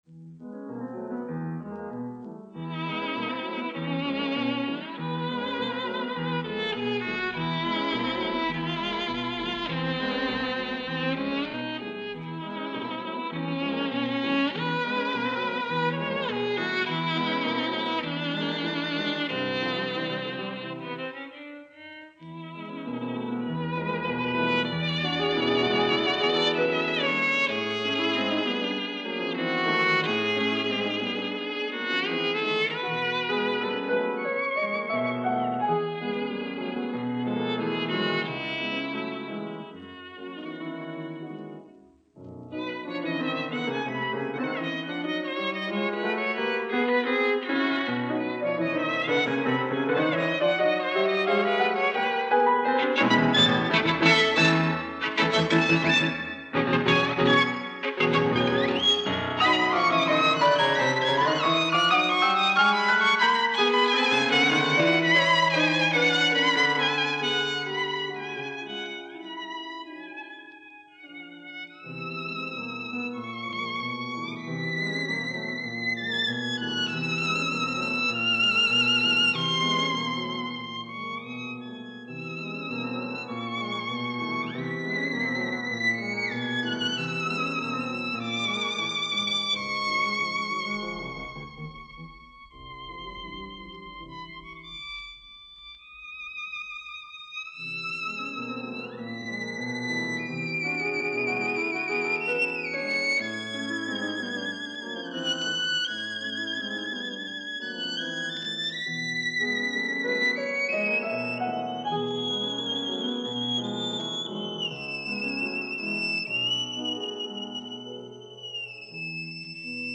скрипка
фортепиано